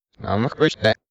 swordman_ack5.wav